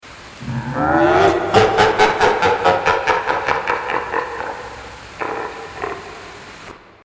Play, download and share Monsterlaugh original sound button!!!!
monsterlaugh.mp3